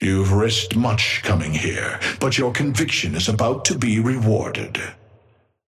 Amber Hand voice line - You've risked much coming here, but your conviction is about to be rewarded.
Patron_male_ally_synth_start_05.mp3